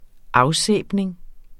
Udtale [ -ˌsεˀbneŋ ]